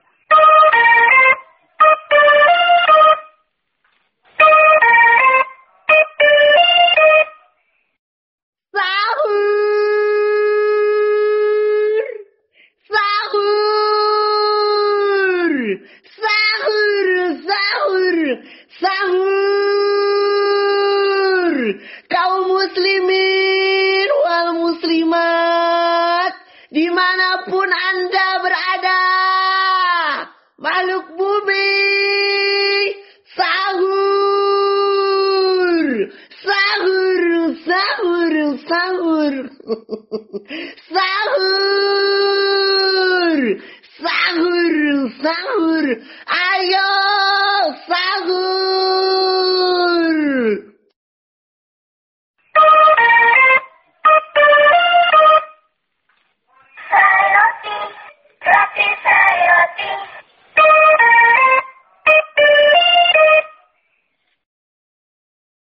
Nada dering alarm
Kategori: Nada dering